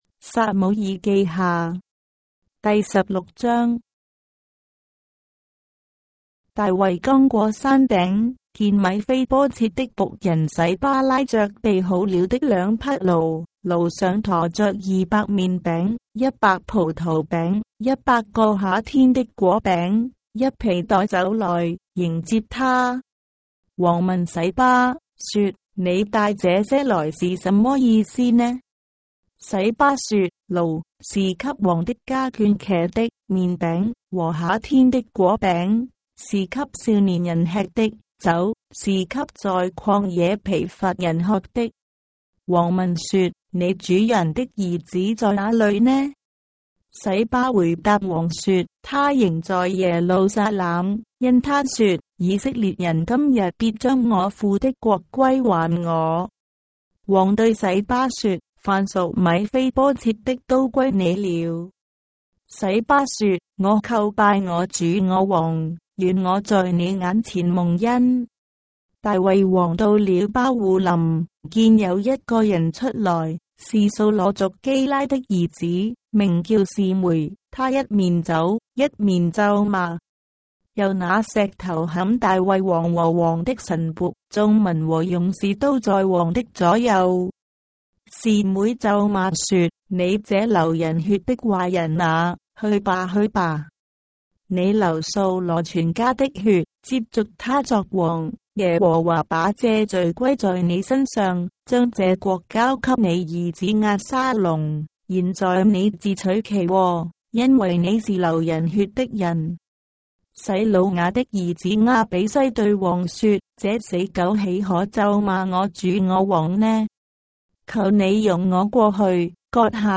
章的聖經在中國的語言，音頻旁白- 2 Samuel, chapter 16 of the Holy Bible in Traditional Chinese